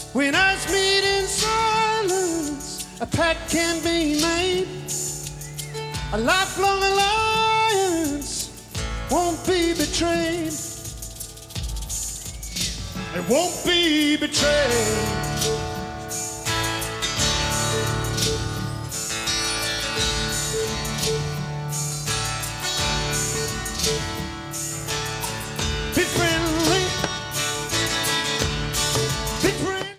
Pre-FM Radio Station Reels